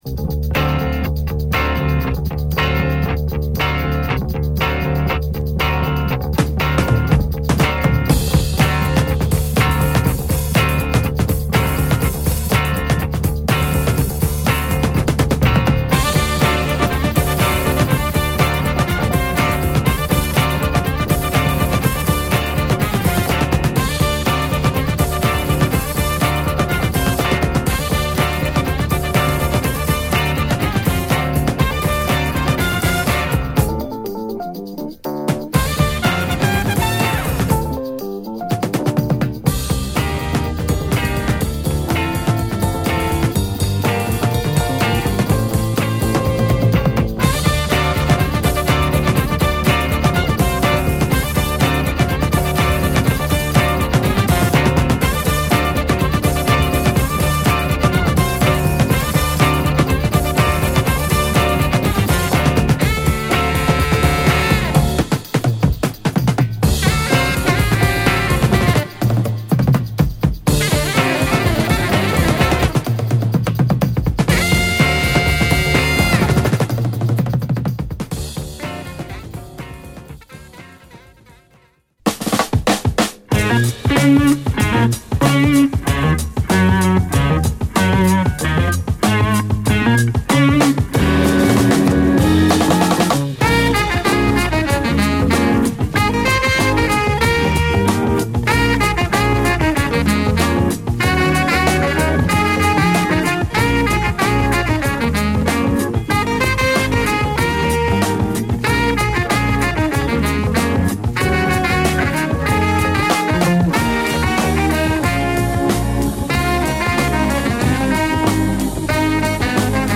ラグドなギター、キーボード、ホーンのコンビネーションでダンサンブルなファンク・グルーヴをタイトに聴かせるA1
エッジの利いたギターとファズトーンのオルガンを軸にブレイクからバッチりのジャズファンクを聴かせるA4
パーカッションとアコースティック・ギターとフルートの演奏が織り成す南米的な牧歌性を感じるA5
ヴィブラフォンを交えたジャズファンクA6